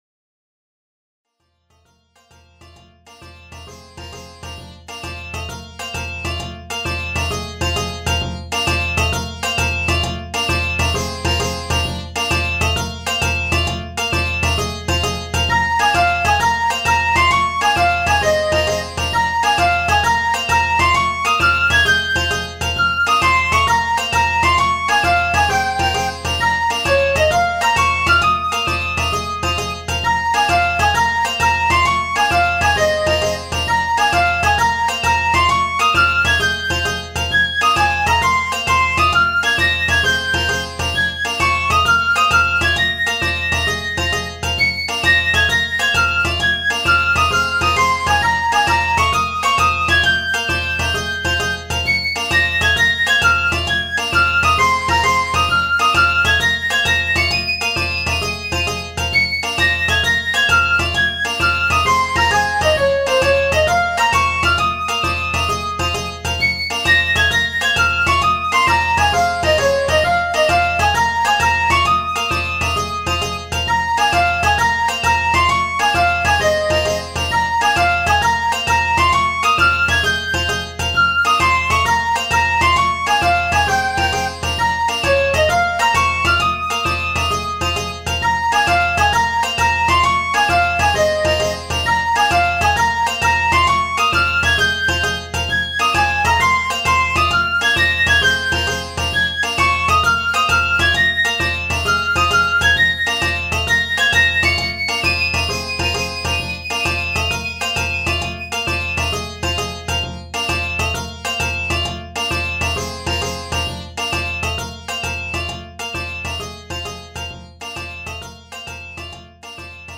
ロング暗い民族